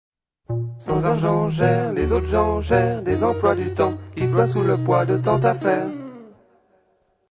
A music of feelings like some old out of time whisky.